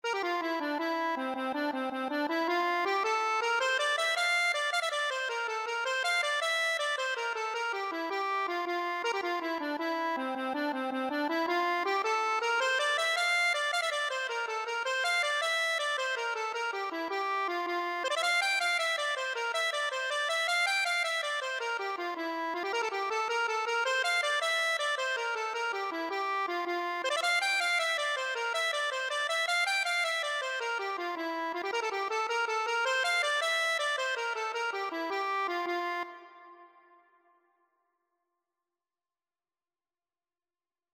6/8 (View more 6/8 Music)
C5-G6
F major (Sounding Pitch) (View more F major Music for Accordion )
Accordion  (View more Easy Accordion Music)
Traditional (View more Traditional Accordion Music)